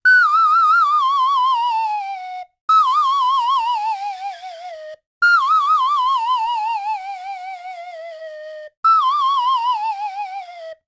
Wood slide whistle - cartoon fall
cartoon droop drop fall slide slide-whistle slip toy sound effect free sound royalty free Movies & TV